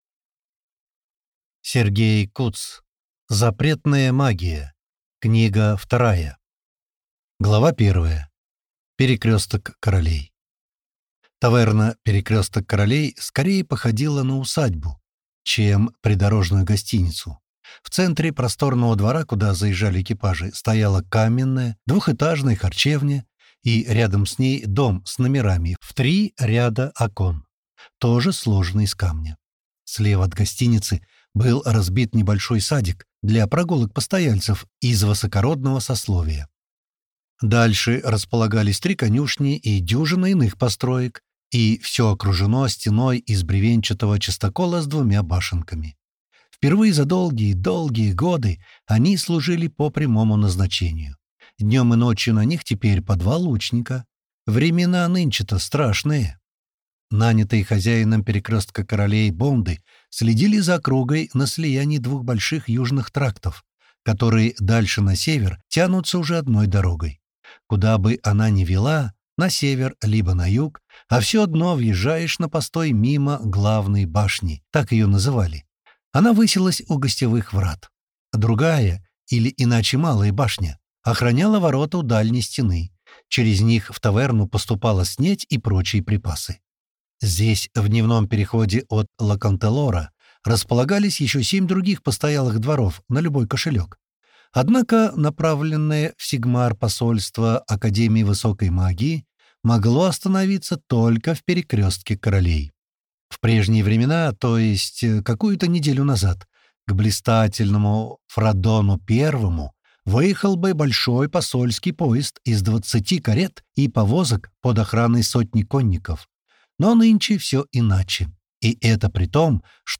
Аудиокнига Запретная магия | Библиотека аудиокниг